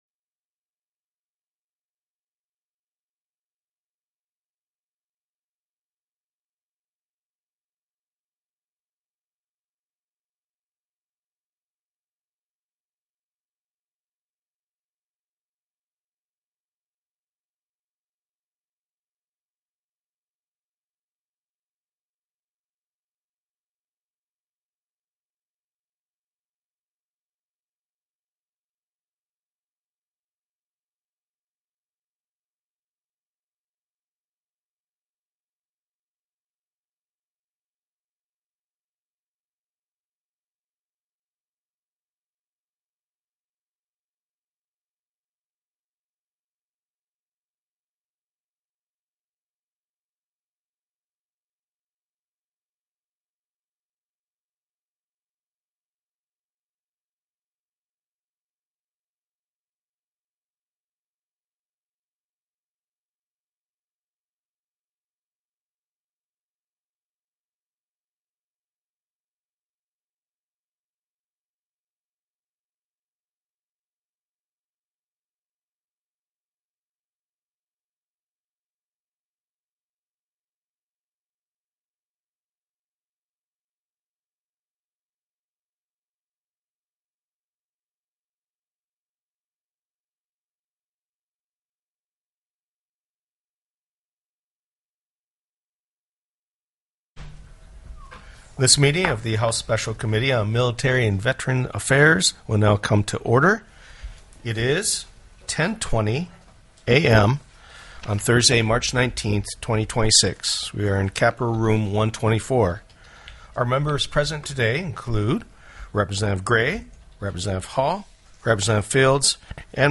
The audio recordings are captured by our records offices as the official record of the meeting and will have more accurate timestamps.
+= HB 363 ALCOHOL SALES BY PATRIOTIC ORGANIZATIONS TELECONFERENCED